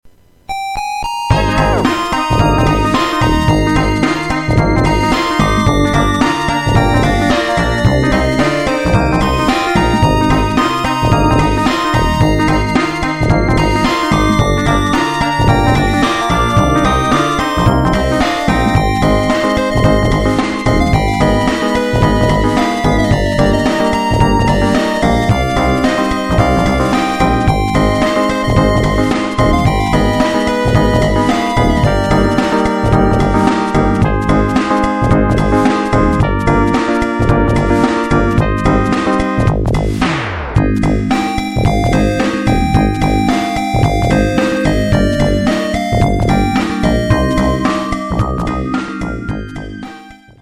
I agree with the filename that this is something written for the Yamaha YM2413 (OPLL) chip meaning it may originate in the MSX computer scene.